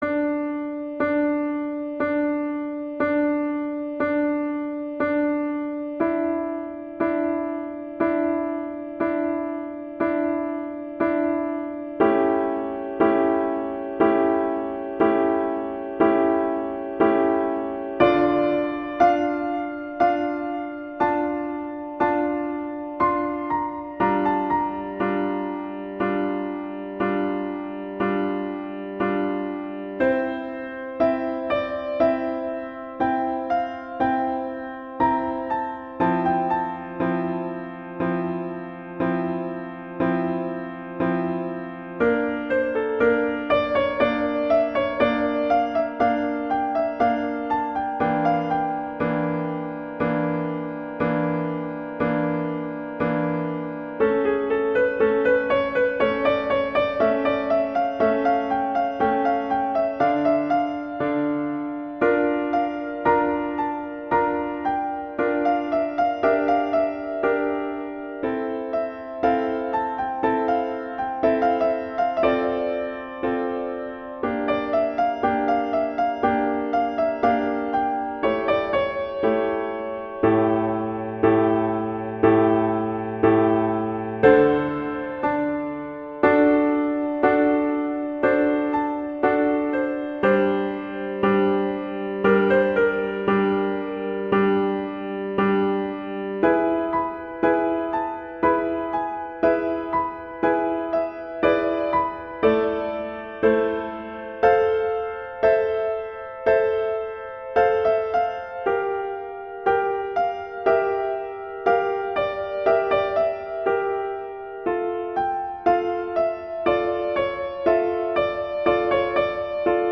Instrumentation: piano solo
arrangement for piano solo
classical
F major
♩=30 BPM (real metronome 40 BPM)